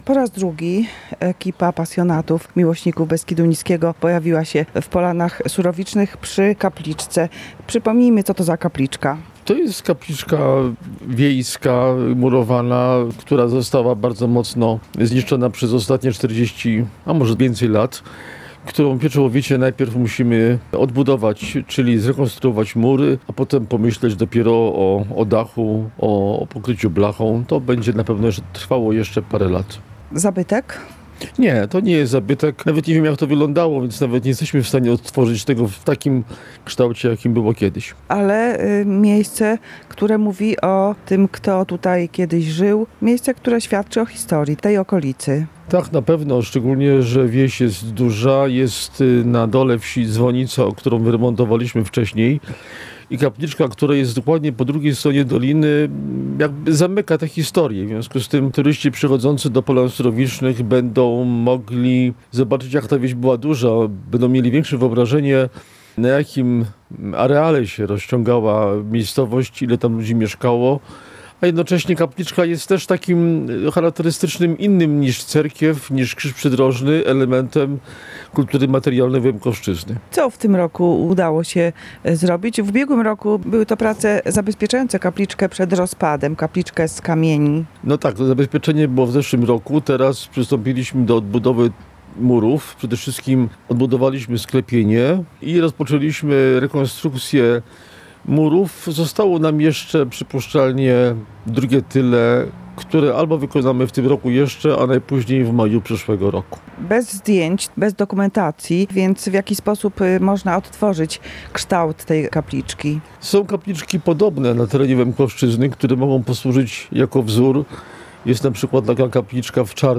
W rozmowie z nami zwrócił uwagę na to, że choć kapliczka to nie zabytek, to i tak jest ona cennym materialnym śladem dawnych mieszkańców, obecnie niezamieszkałej doliny Potoku Surowicznego.